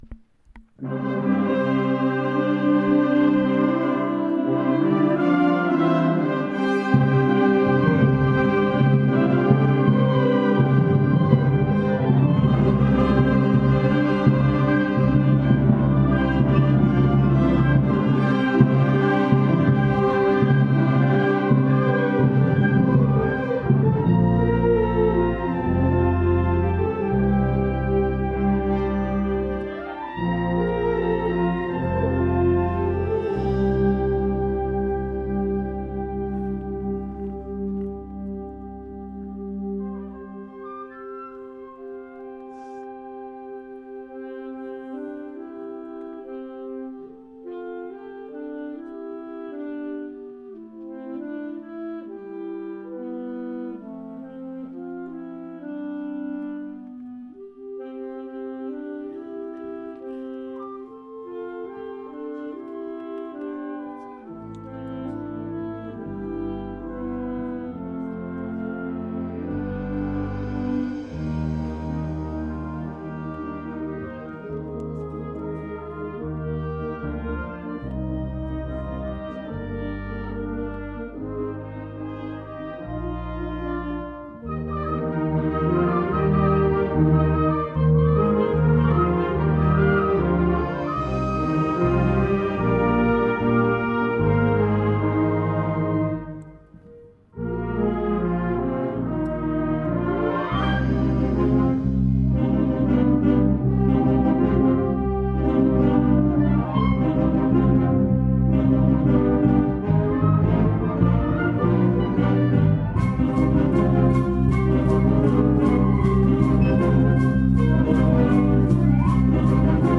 Concert à Laon
Programme du Concert - Cliquez sur le titre pour écouter